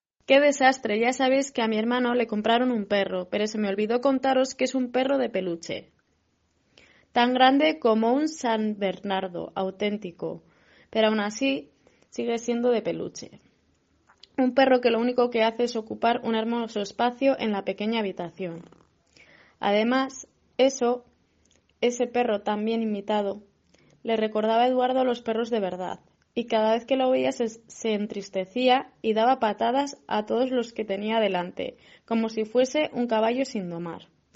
Audio del ejercicio 2: Dictado